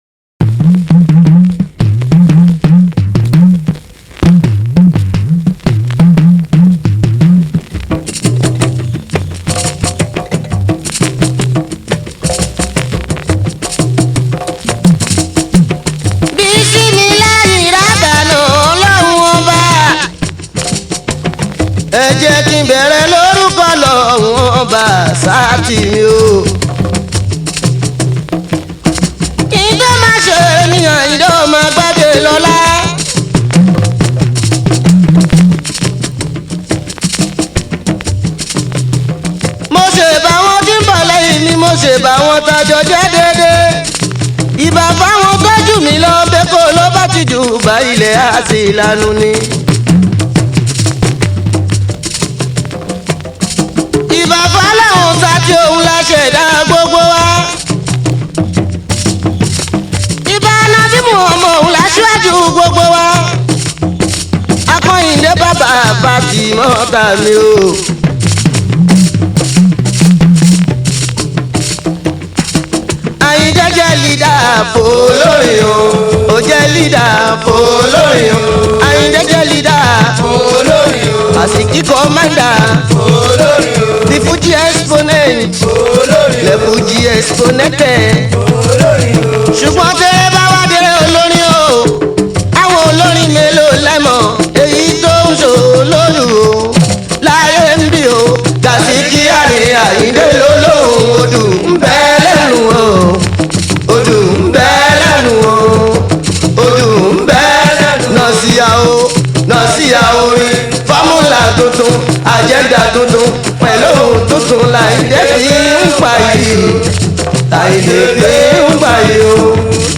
Yoruba Fuji song